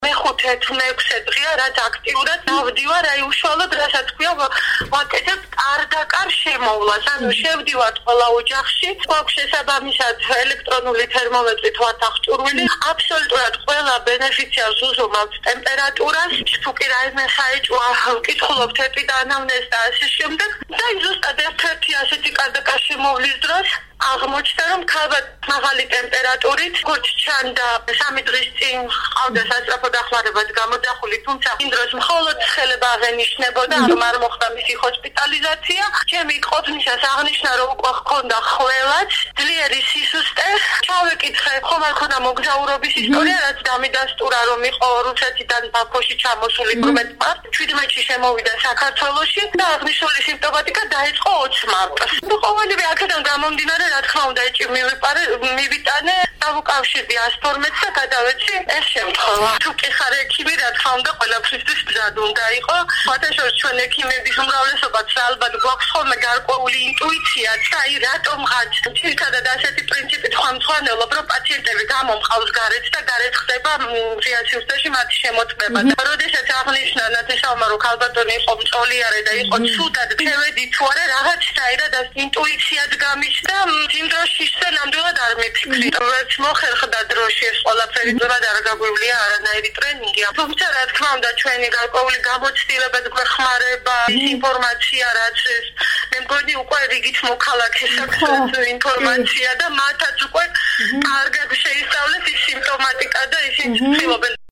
როდესაც რადიო თავისუფლება ექიმს ტელეფონით დაუკავშირდა, ის კვლავ შემოვლაზე იყო.